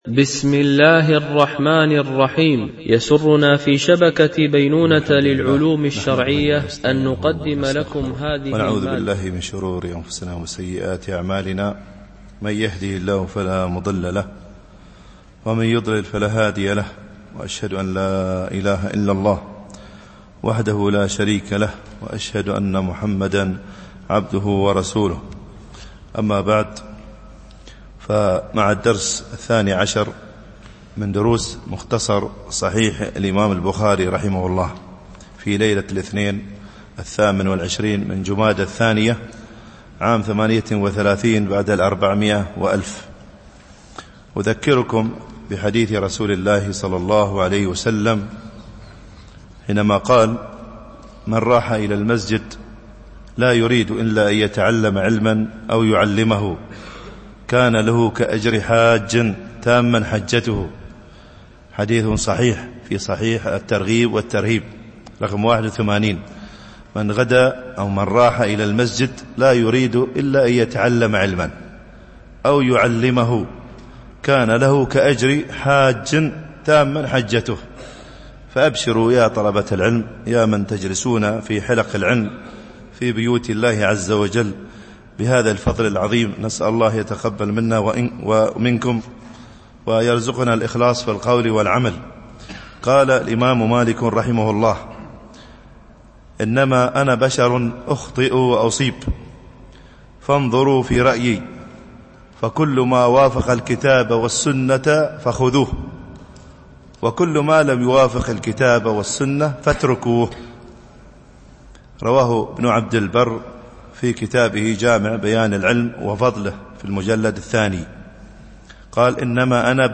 شرح مختصر صحيح البخاري ـ الدرس 12 (الحديث 26)